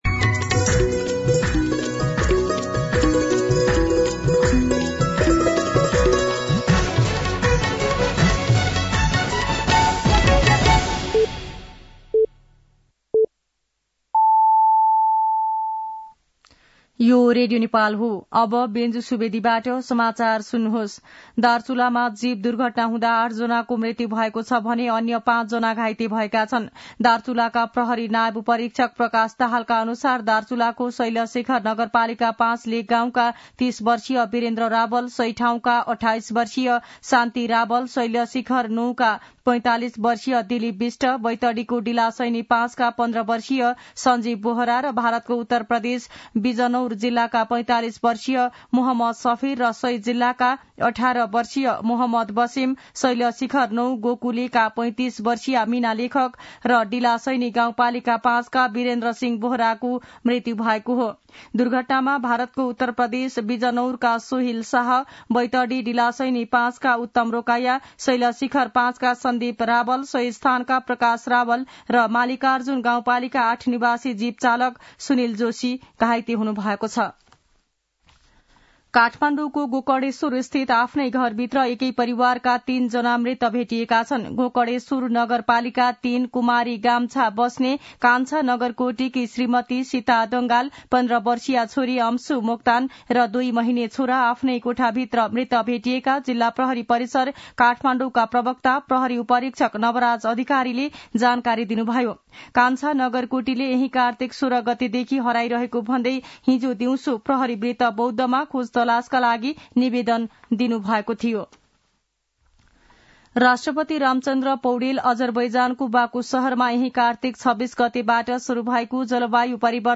मध्यान्ह १२ बजेको नेपाली समाचार : १ मंसिर , २०८१
12-pm-Nepali-News-1.mp3